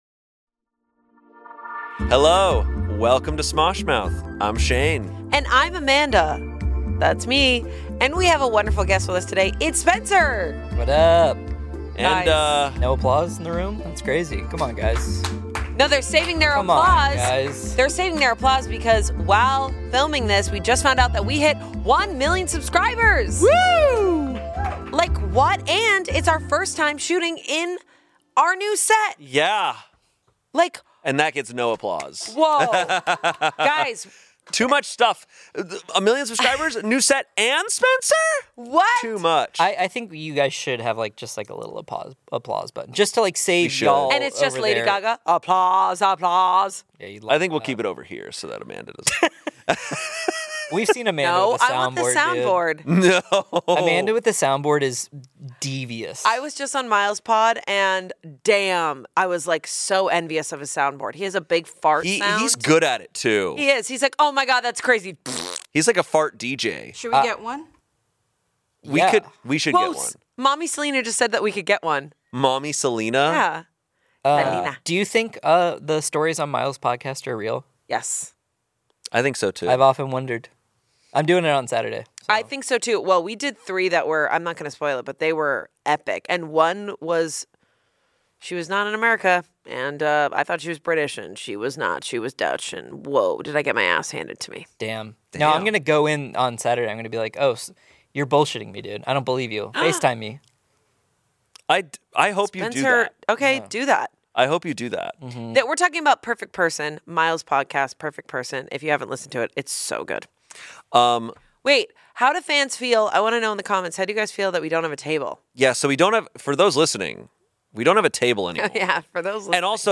We hit ONE MILLION subscribers AND it's our first episode in our NEW STUDIO!!!